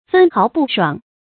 分毫不爽 注音： ㄈㄣ ㄏㄠˊ ㄅㄨˋ ㄕㄨㄤˇ 讀音讀法： 意思解釋： 比喻沒有絲毫差錯。